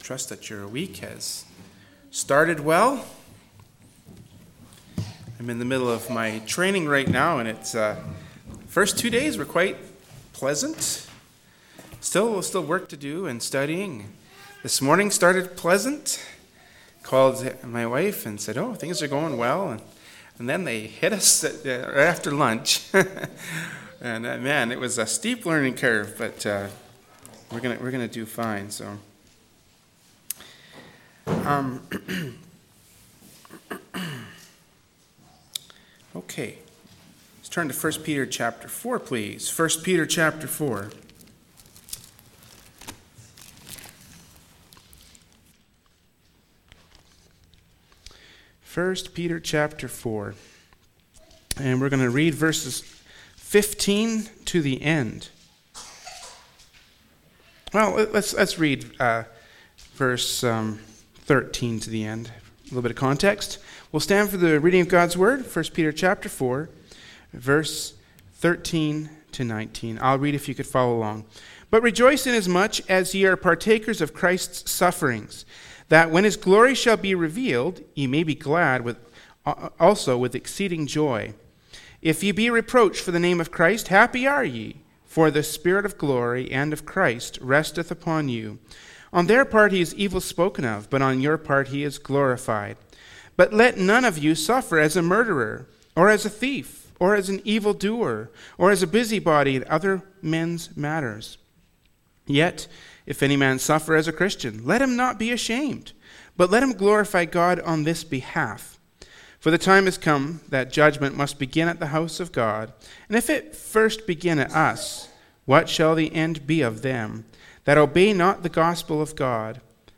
Service Type: Wednesday Evening Service